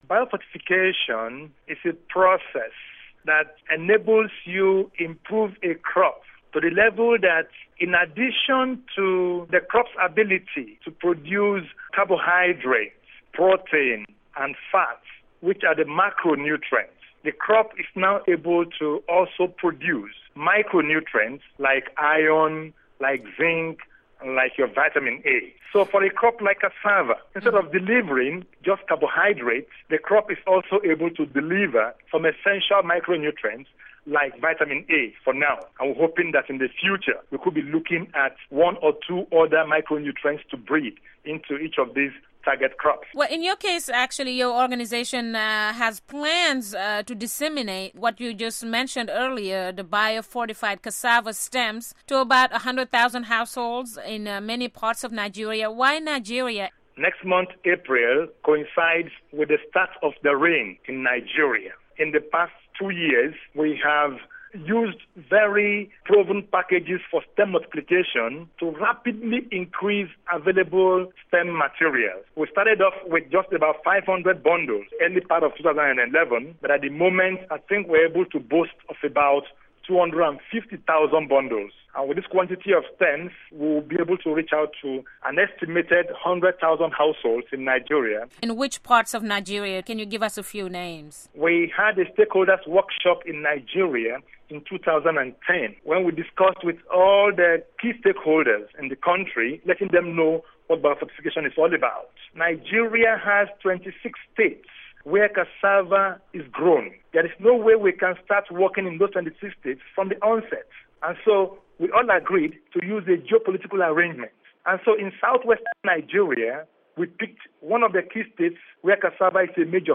Listen to an interview on biofortification efforts in Nigeria